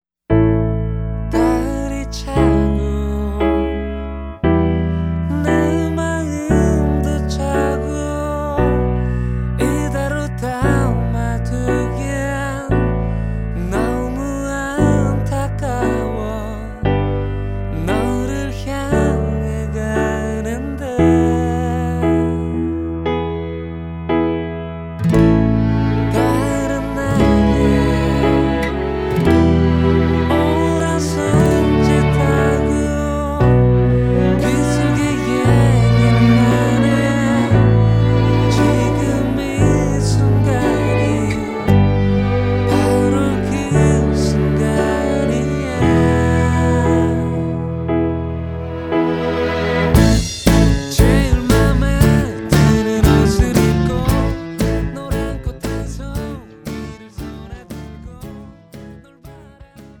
음정 원키
장르 가요 구분
가사 목소리 10프로 포함된 음원입니다